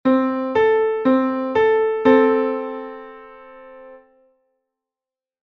A Major 6th is an interval of four whole-steps and one half-step.
major-6.mp3